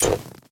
repair_wolf4.ogg